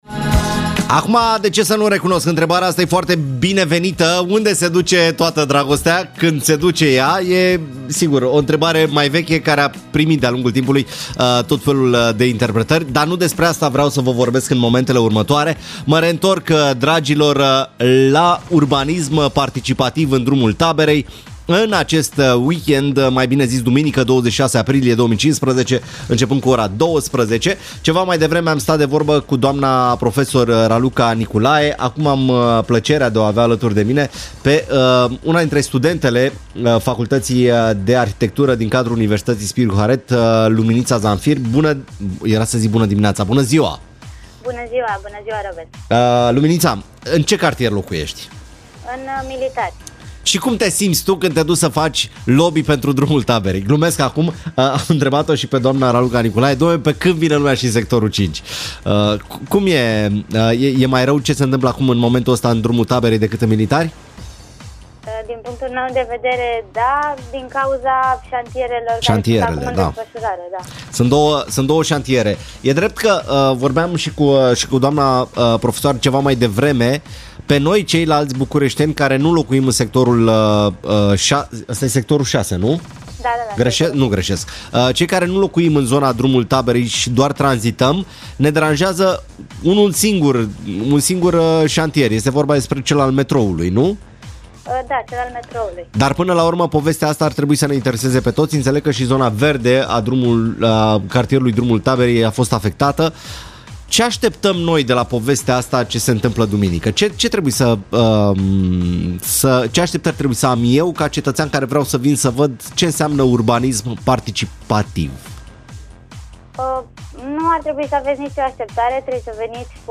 Interviu H-FM